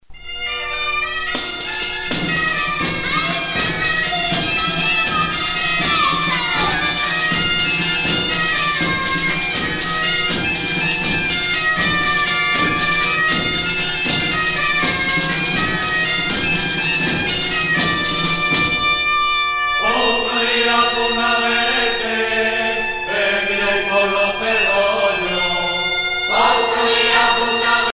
danse : jota (Espagne)
Pièce musicale éditée